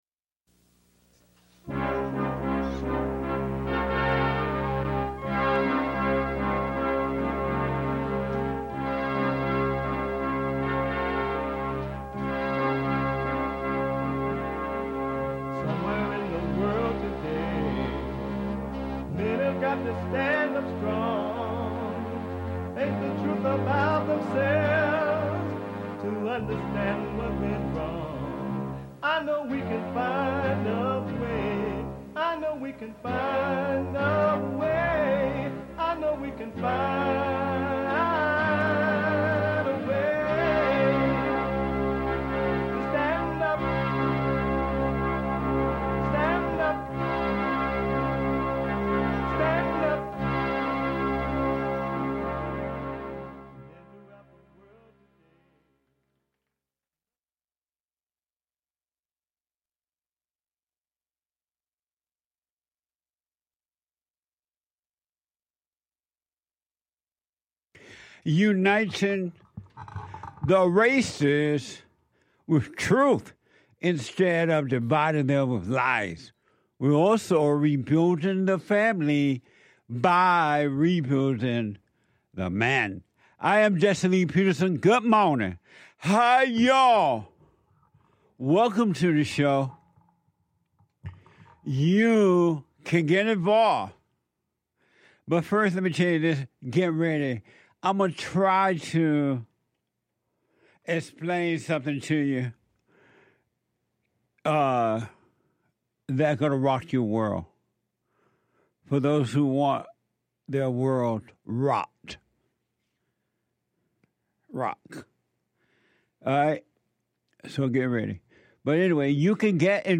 The Jesse Lee Peterson Radio Show